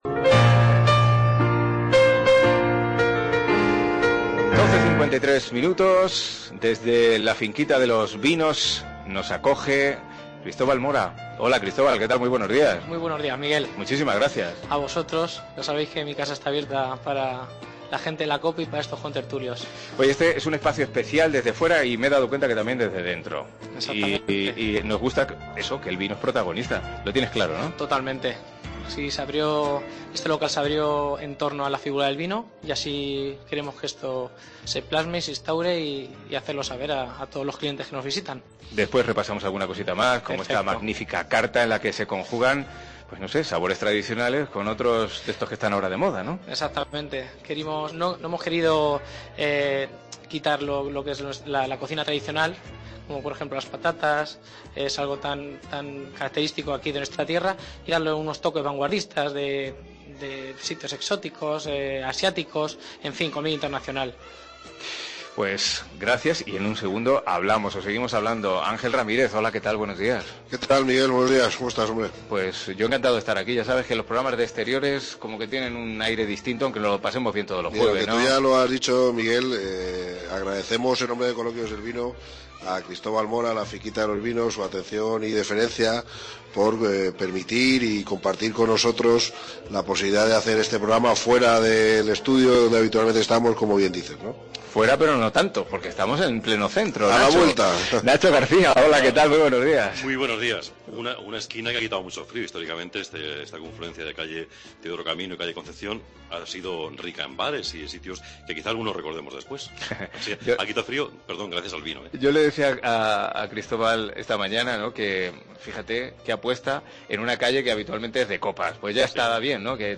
Coloquios del Vino se ha emitido hoy desde La Finquita de los Vinos, en la calle Gaona